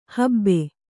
♪ habbe